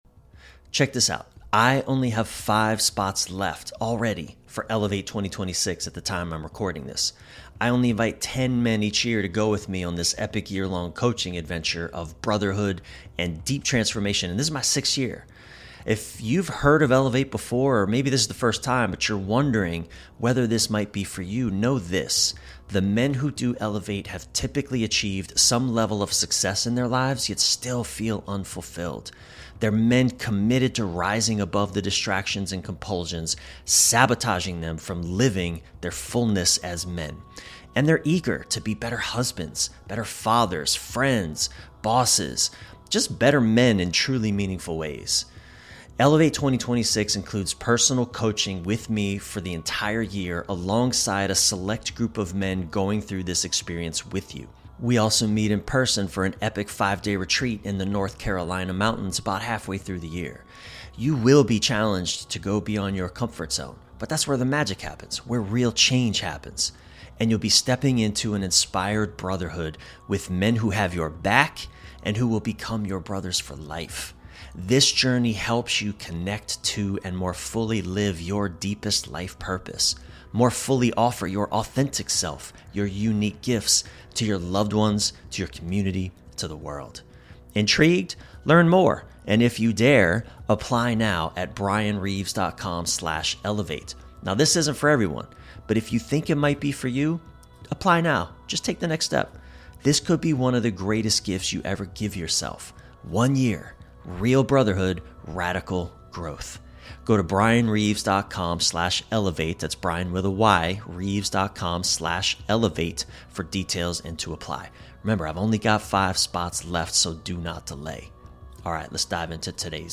In this heartfelt solo episode of Bridging Connections